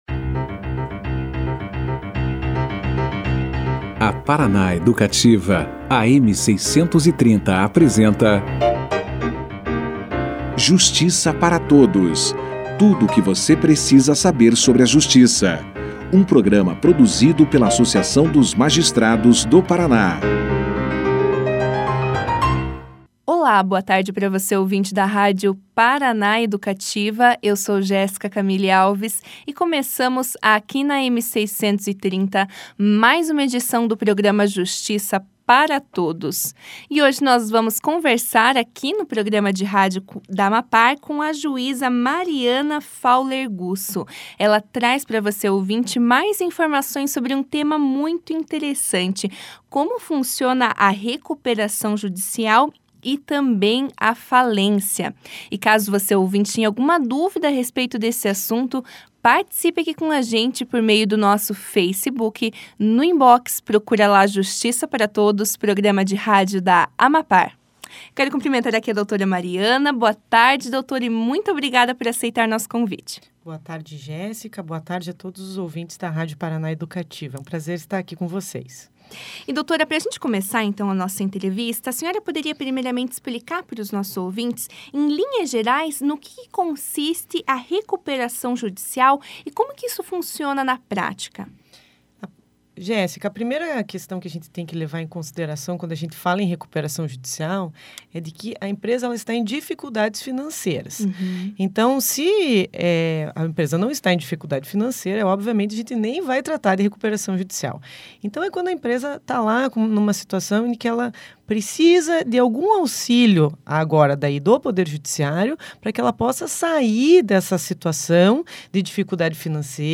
Recuperação judicial e falência foram os temas discutidos no Justiça para Todos, da sexta- feira (17). Quem trouxe mais informações e esclarecimentos sobre o assunto foi a juíza Mariana Fowler Gusso.
Durante a entrevista, a magistrada também esclareceu o que significa a declaração de falência.